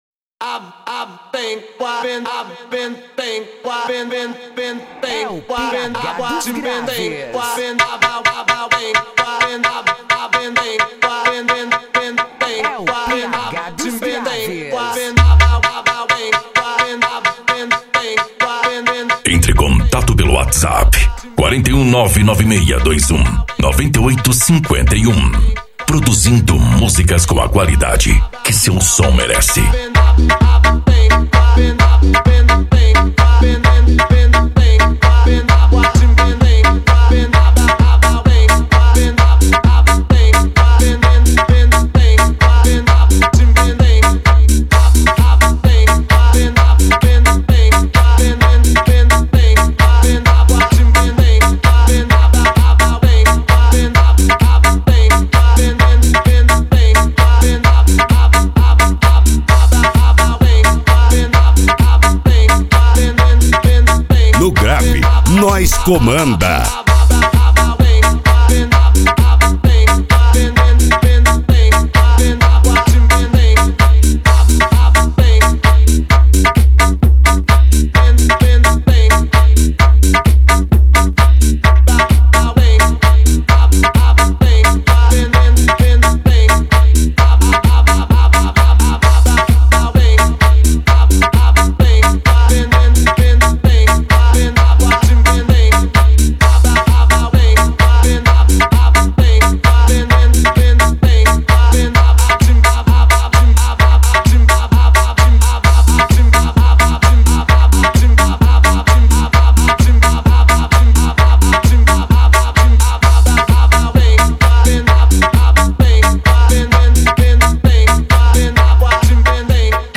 Bass
Racha De Som